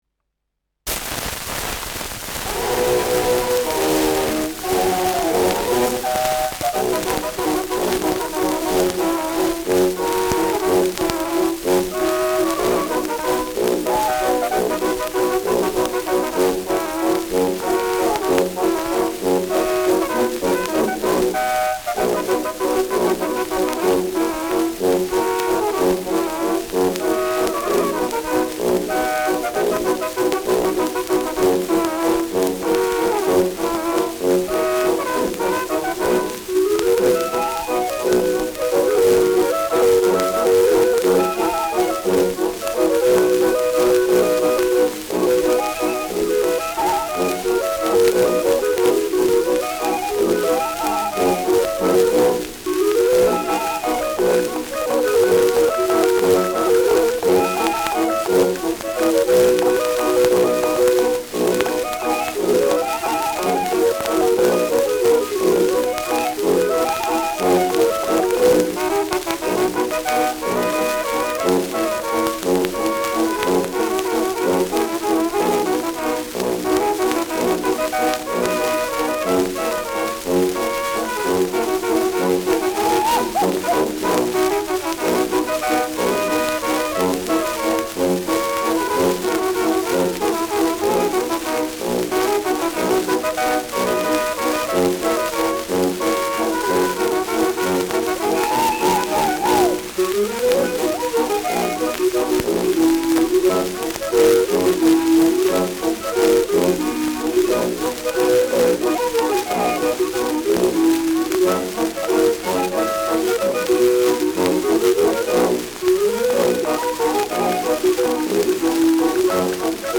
Schellackplatte
starkes Rauschen : präsentes Knistern : abgespielt : leiert : gelegentliches Knacken
Kapelle Peuppus, München (Interpretation)
Mit Klopfgeräuschen und Juchzern.
[München] (Aufnahmeort)